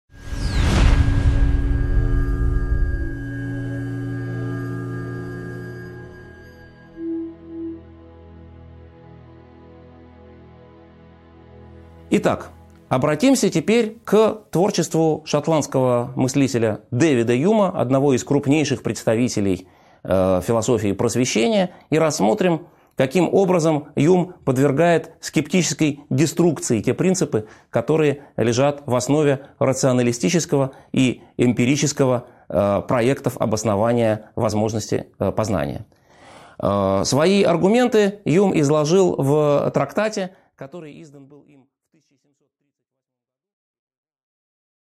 Аудиокнига 10.2 Скептические аргументы против рационализма и эмпиризма | Библиотека аудиокниг